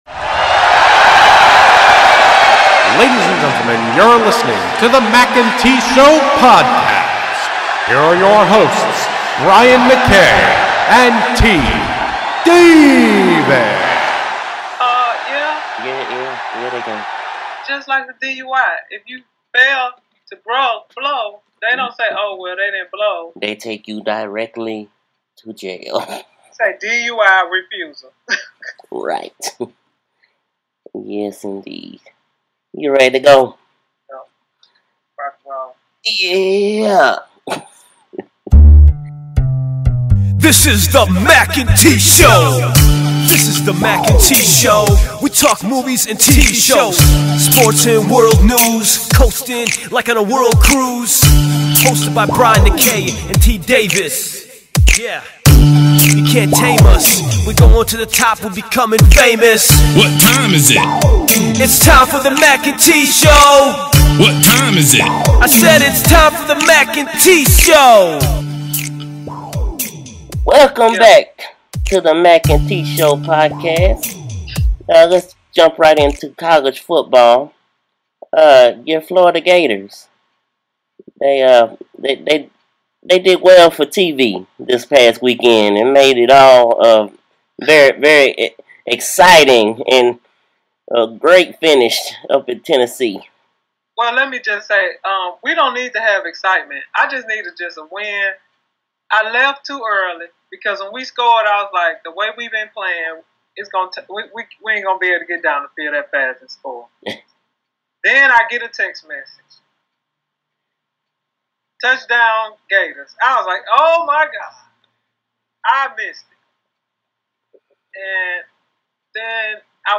Ring Announcer Intro